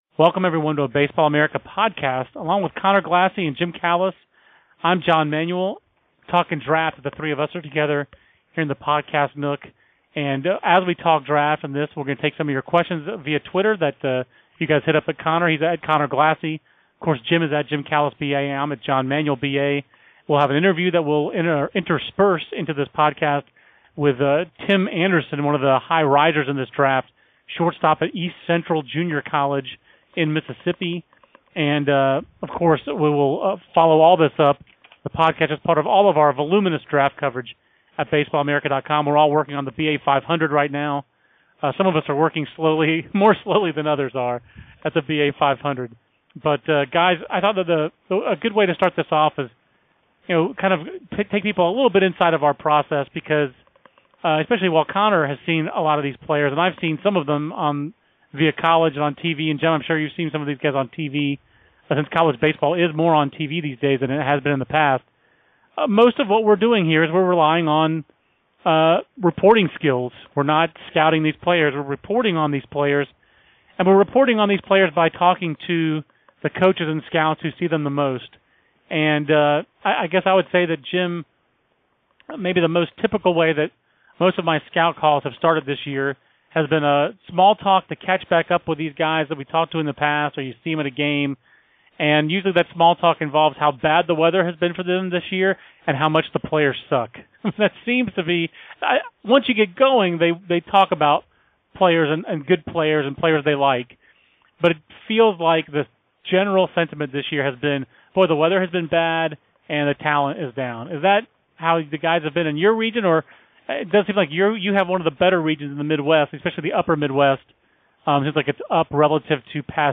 A trio of draft experts talk potential first-rounders and more